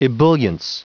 Prononciation du mot ebullience en anglais (fichier audio)
Prononciation du mot : ebullience